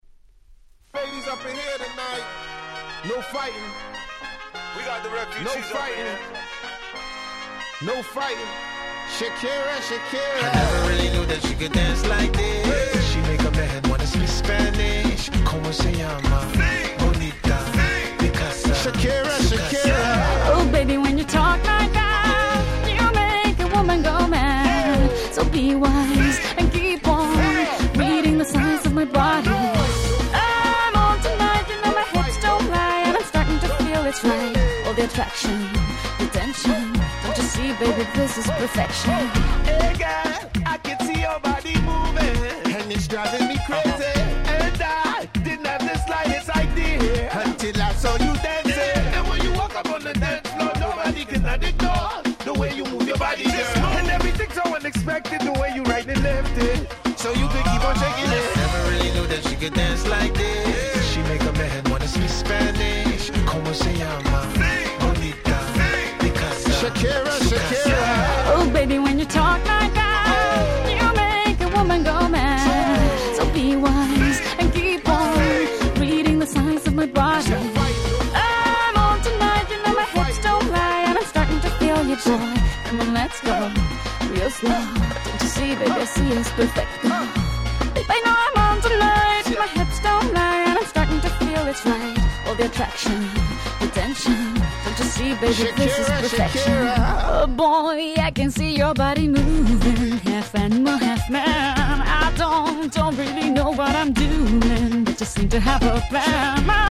05' Super Hit Reggaeton/R&B !!
イントロドン！など頭から盛り上がり必至の問答無用のParty Tune !!